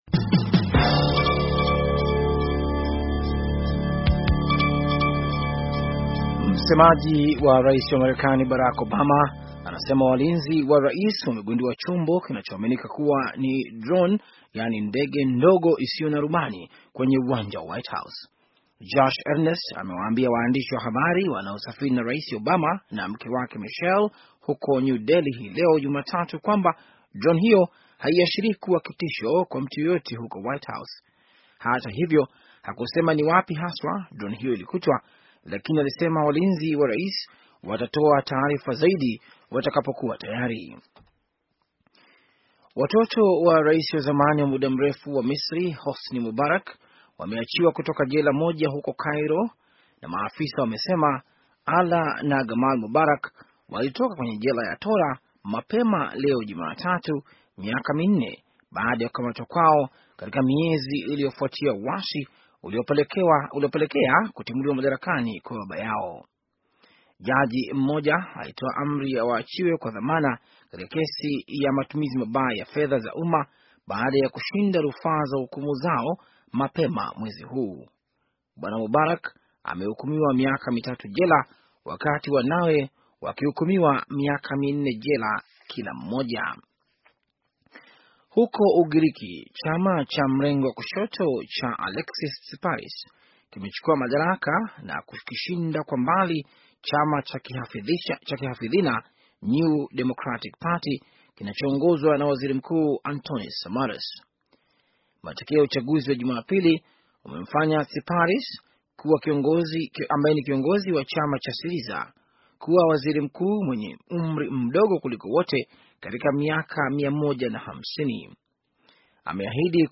Taarifa ya habari - 4:08